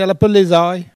Elle crie pour appeler les oies